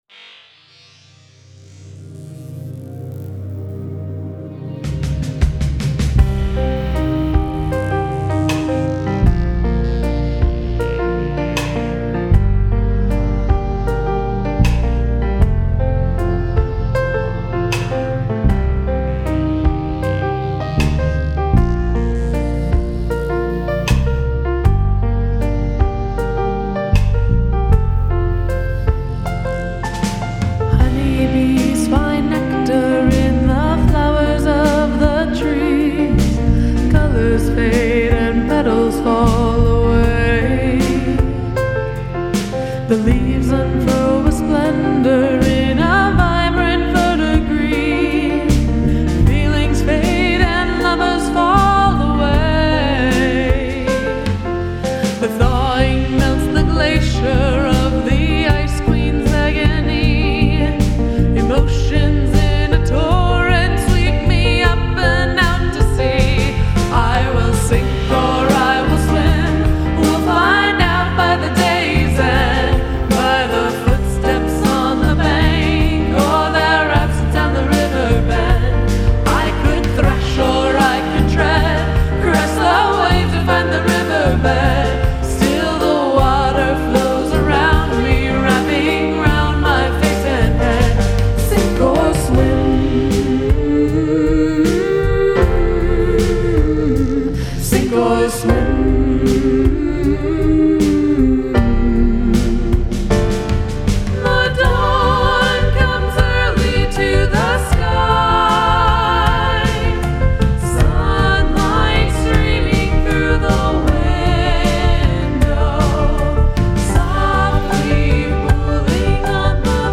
Nice backing vocals.
Vocals are really well done.